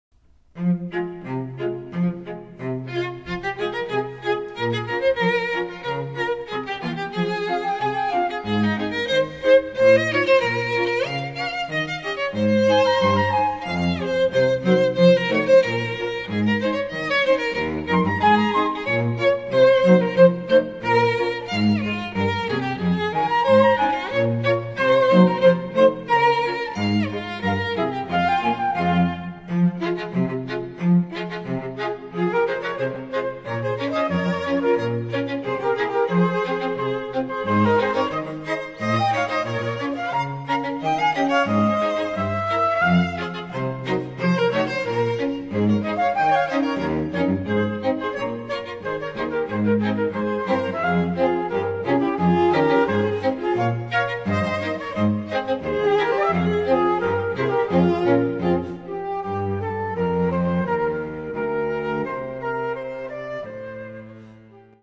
(Flute, Violin, Viola and Cello)
MIDI